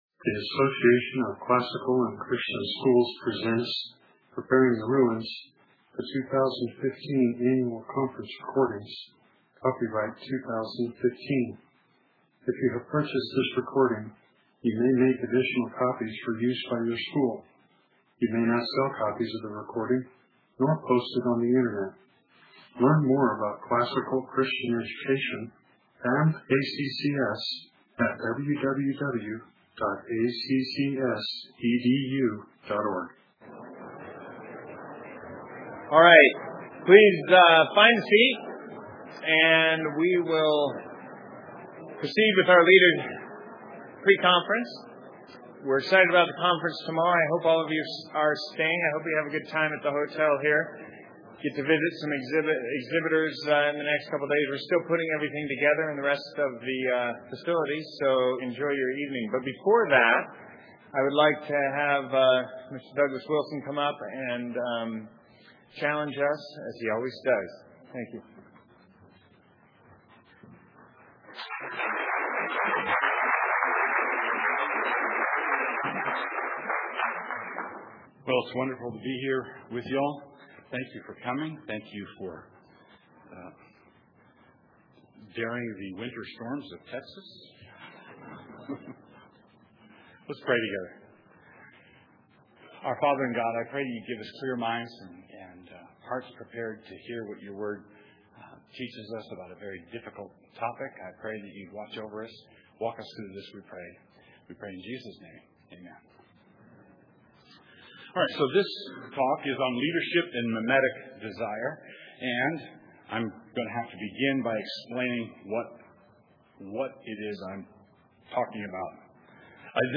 2015 Leaders Day Talk | 0:51:51 | Leadership & Strategic
Jan 11, 2019 | Conference Talks, Leaders Day Talk, Leadership & Strategic, Library, Media_Audio | 0 comments